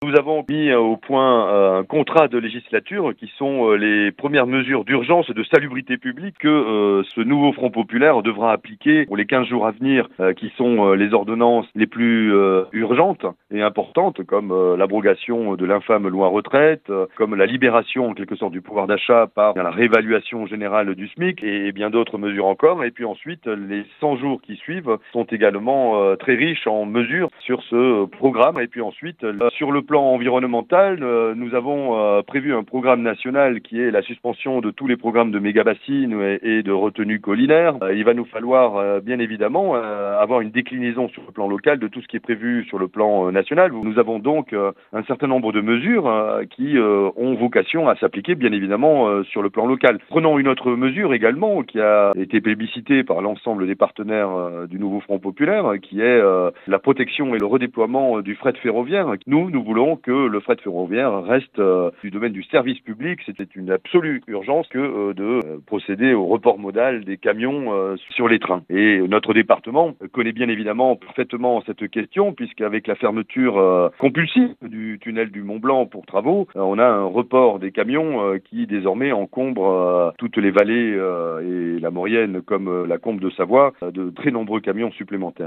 Voici les interviews des 6 candidats de cette 4ème circonscription de Savoie (par ordre du tirage officiel de la Préfecture) :
2    M. COULOMME Jean-François   Candidat Nouveau Front Populaire ( Union de la Gauche)